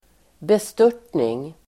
Uttal: [best'ör_t:ning]